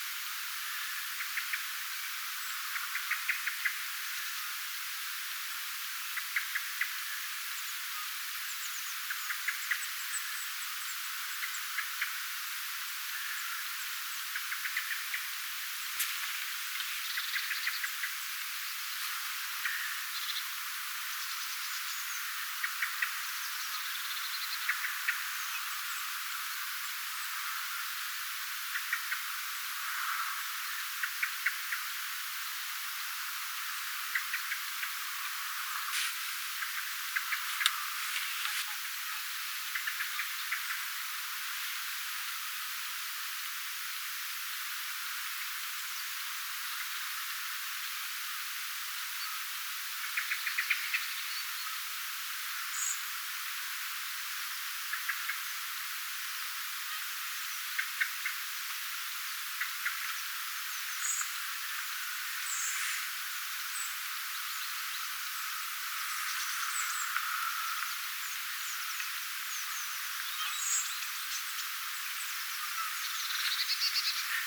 keltanokkarastaslintu ääntelee
keltanokkarastaslintu_aantelee.mp3